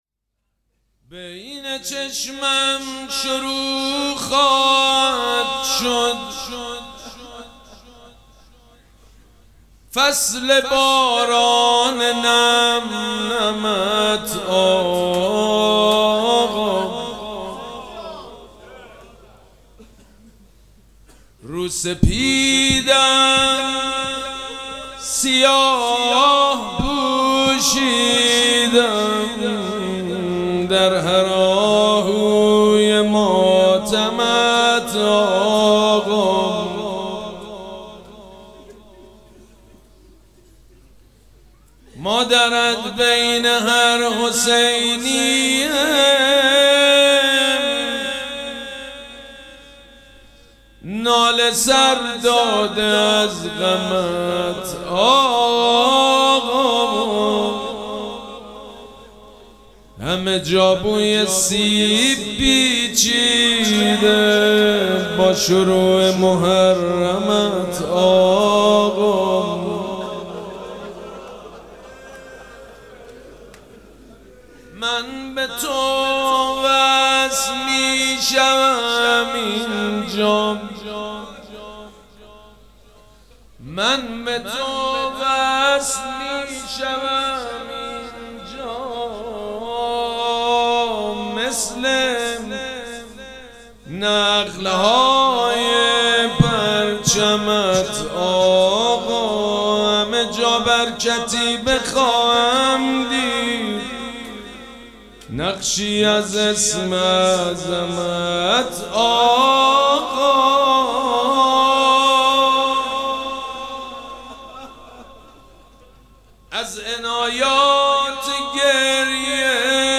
مراسم عزاداری شب اول محرم الحرام ۱۴۴۷ پنجشنبه ۵ تیر ماه ۱۴۰۴ | ۳۰ ذی‌الحجه ۱۴۴۶ حسینیه ریحانه الحسین سلام الله علیها
شعر خوانی مداح حاج سید مجید بنی فاطمه